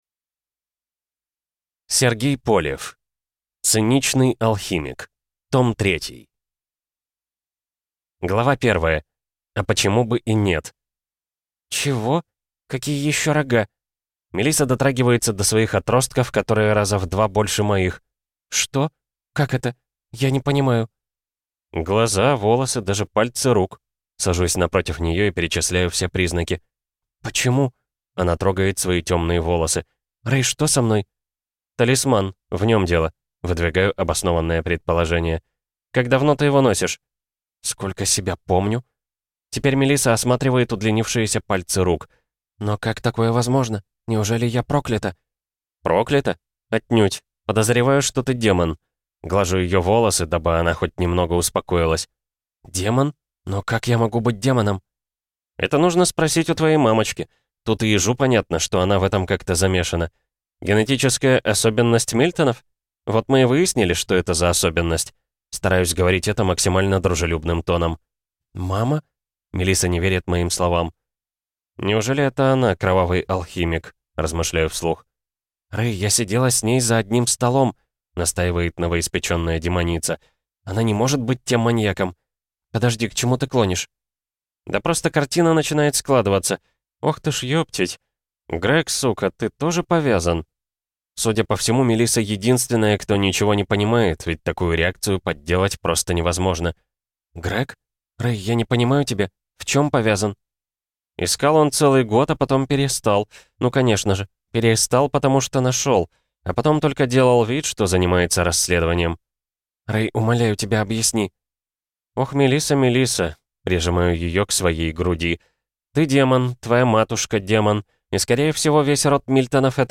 Аудиокнига Циничный алхимик. Том 3 | Библиотека аудиокниг
Прослушать и бесплатно скачать фрагмент аудиокниги